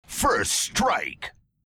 halo reach first strike voice
halo-reach-first-strike-voice.mp3